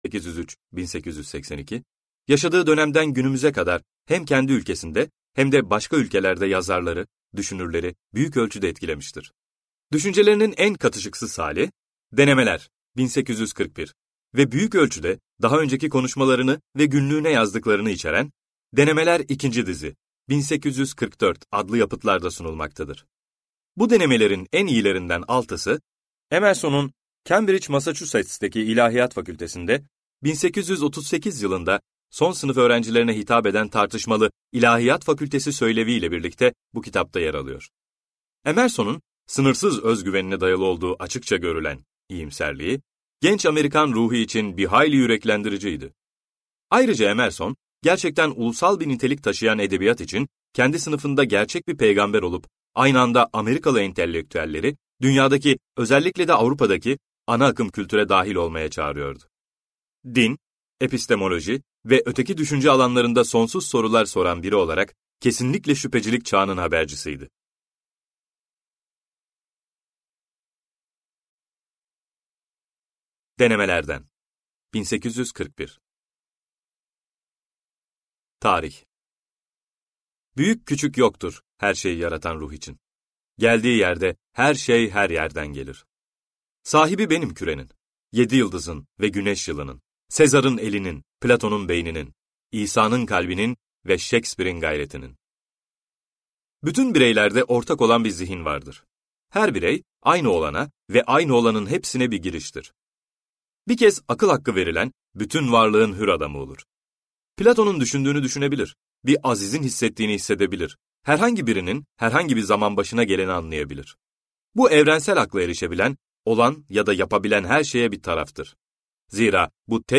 İnsanın Görkemi - Seslenen Kitap